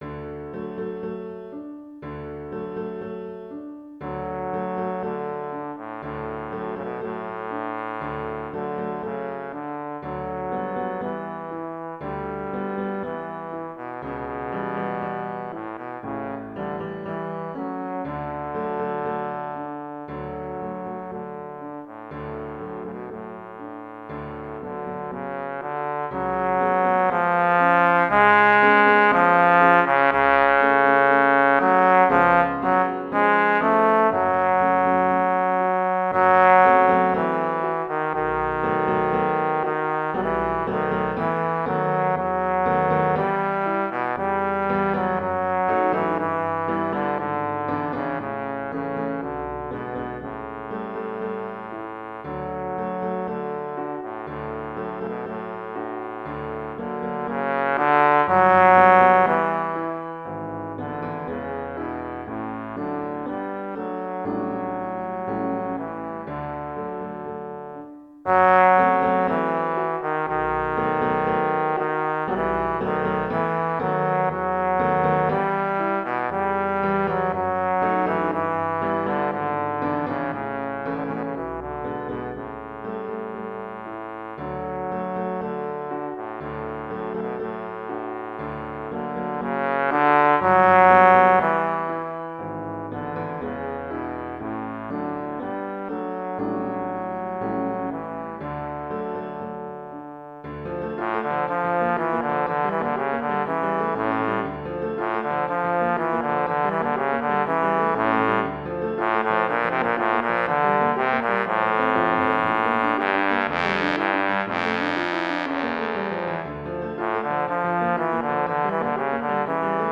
Voicing: Trombone Solo